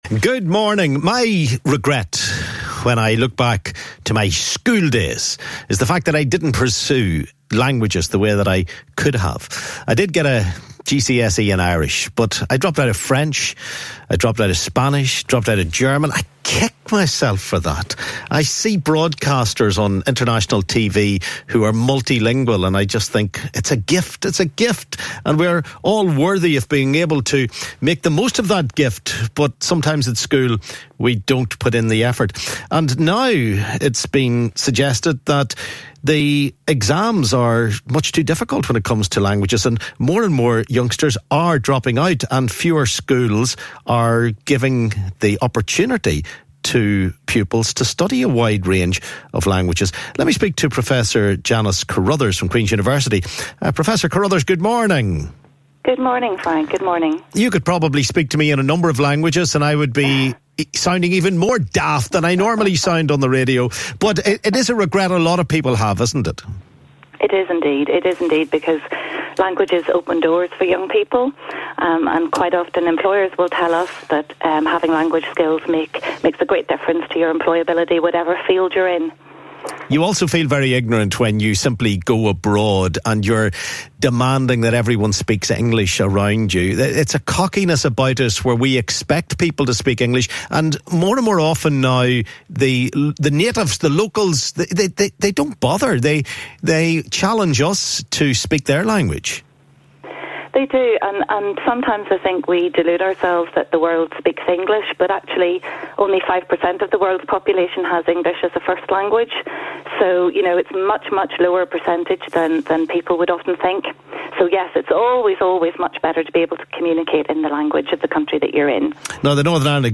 Listen to my reaction on Radio Ulster and Q105 in the clips below.